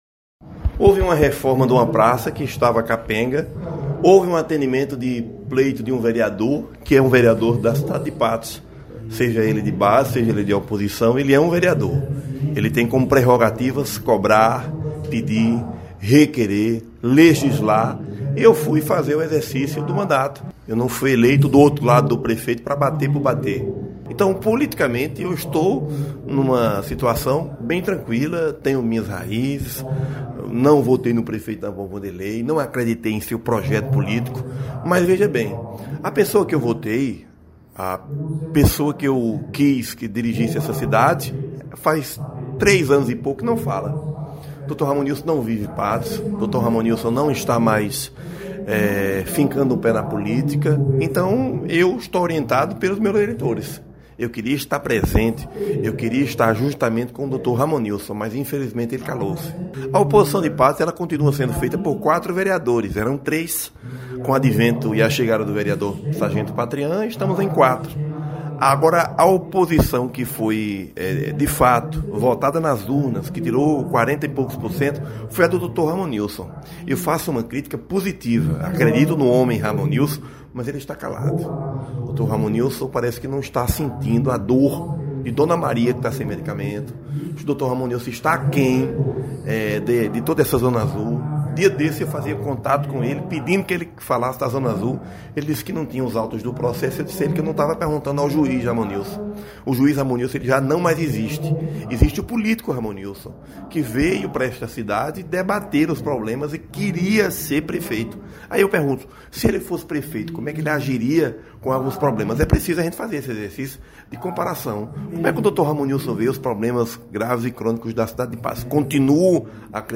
Em recente entrevista concedida a imprensa patoense, o vereador Jamerson Ferreira (PSC) falou sobre atuação política da oposição na cidade de Patos.